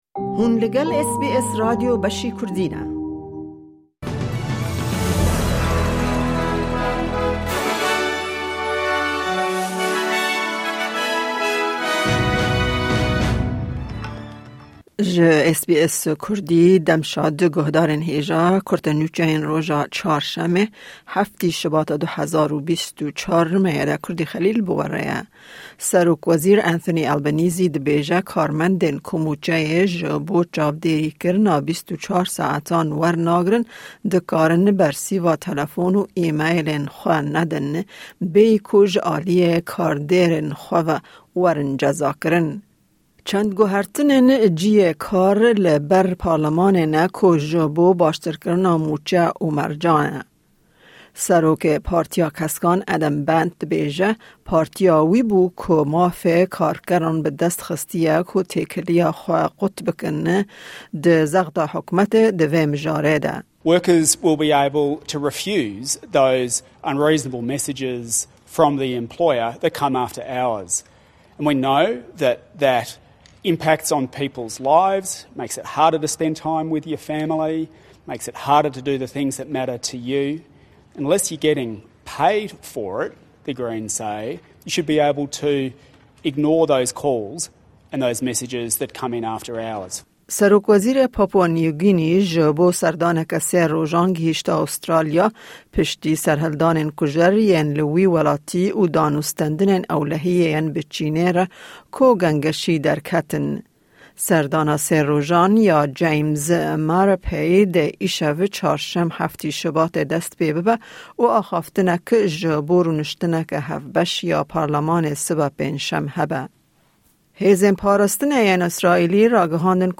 Kurte Nûçeyên roja Çarşemê 7î Şubata 2024